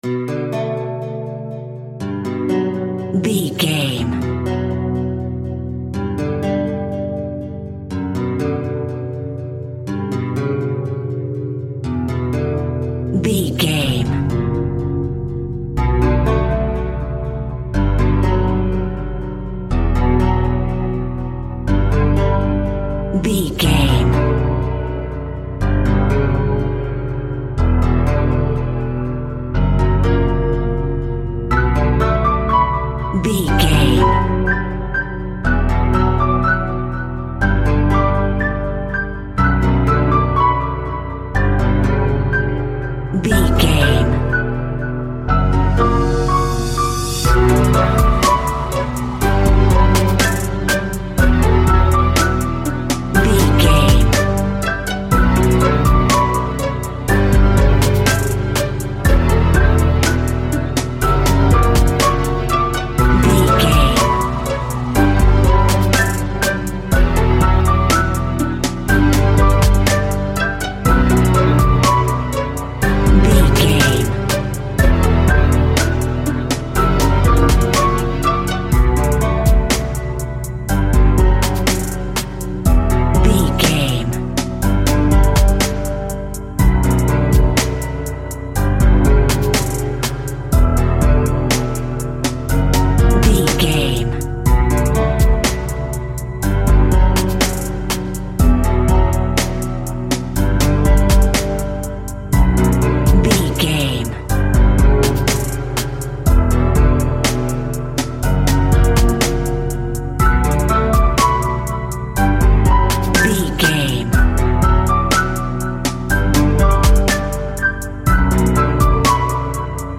Acoustic Guitar Meets Rap.
Aeolian/Minor
Slow
hip hop
hip hop instrumentals
chilled
laid back
groove
hip hop drums
piano